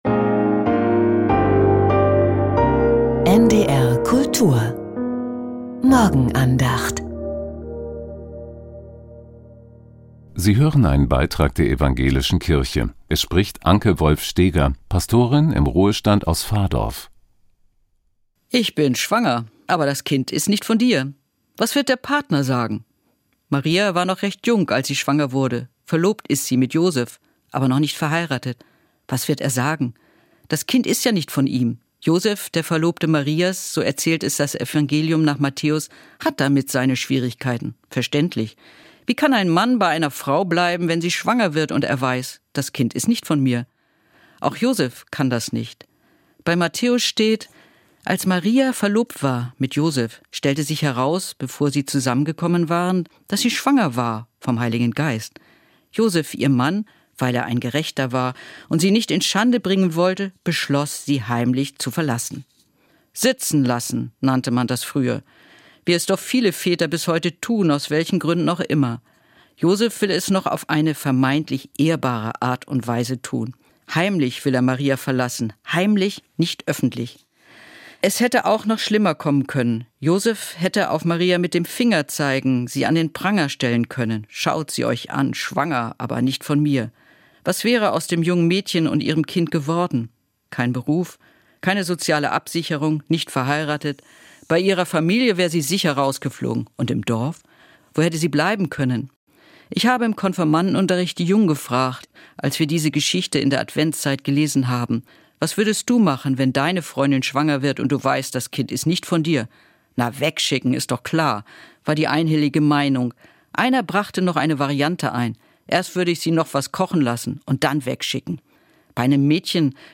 Maria und Josef ~ Die Morgenandacht bei NDR Kultur Podcast